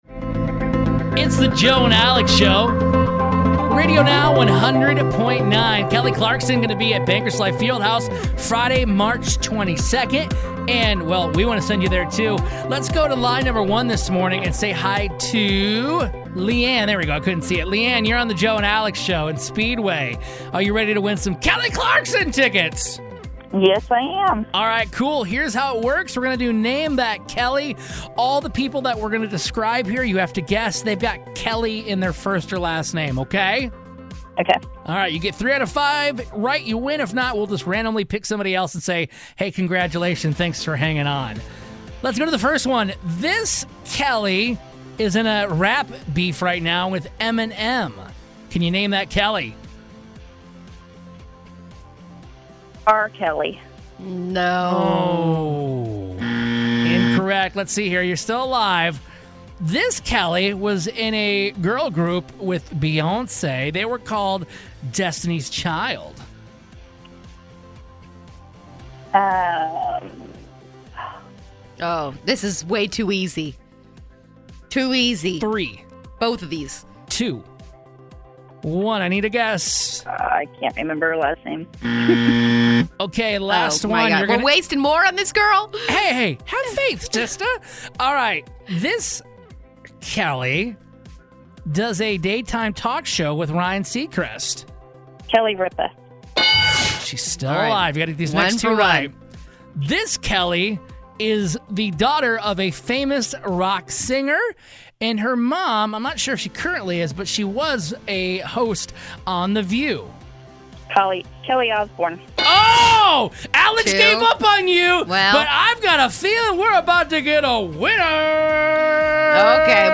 We give a listener clues about a certain celebrity with the name "Kelly" in it to guess in order to win a pair of Kelly Clarkson tickets.